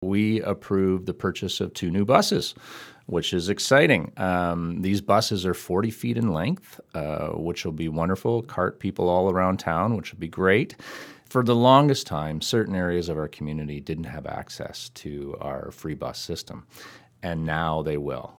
Town of Orangeville Council has approved a purchase which will further enhance local transit. Orangeville Deputy Mayor, Todd Taylor explains what was done: